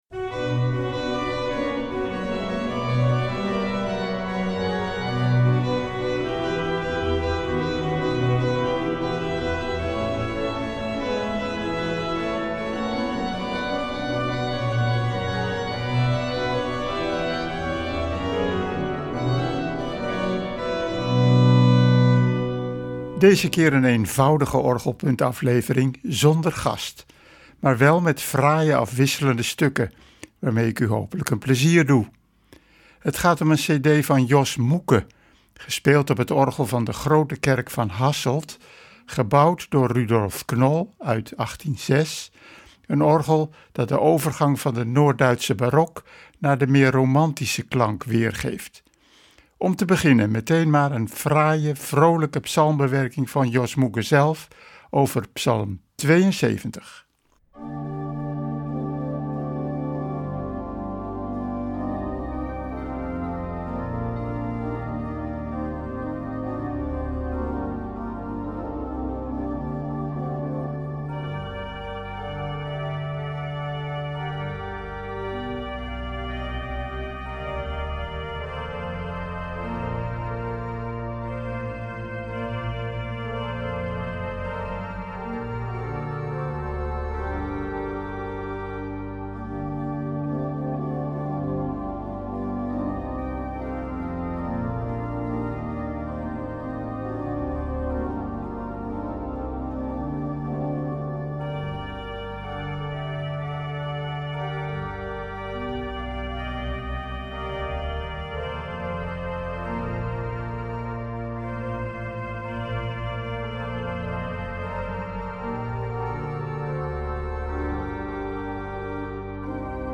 gespeeld op het Knol-orgel
van de Grote Kerk in Hasselt
werken uit de romantiek